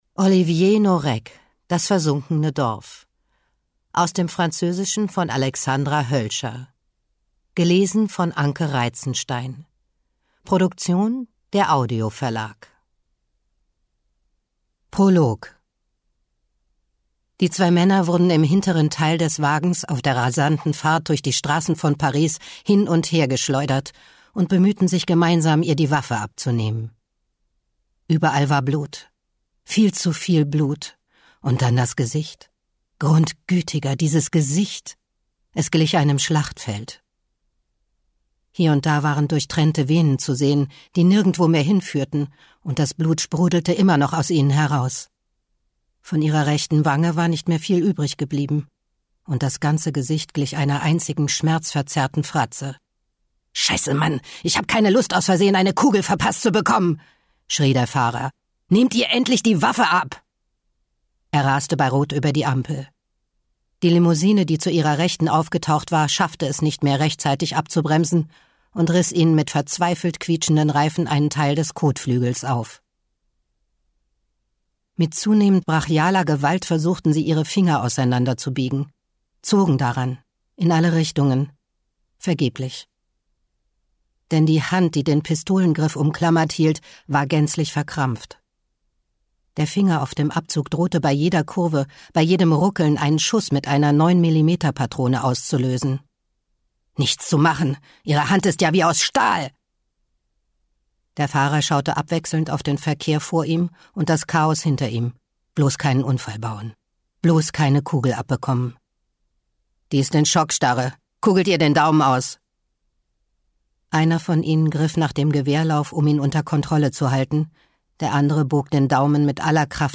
Das versunkene Dorf Krimi-Lesung
Ungekürzte Lesung